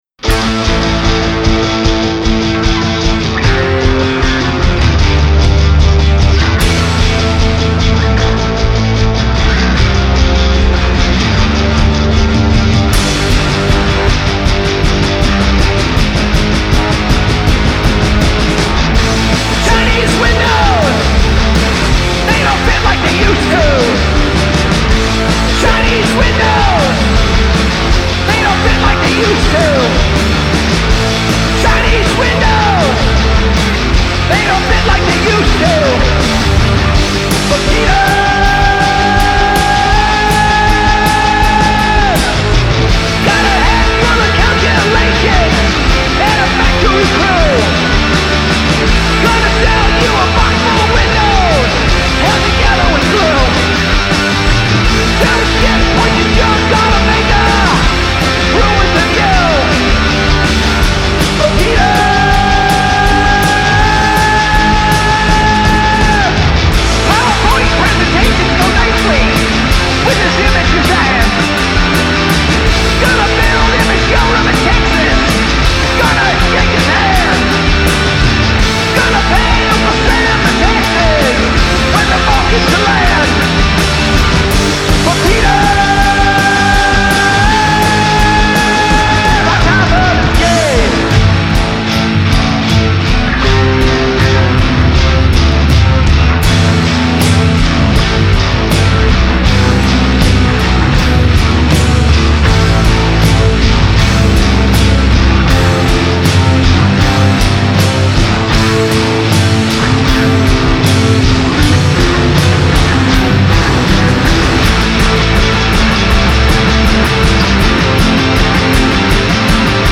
guitarist and vocalist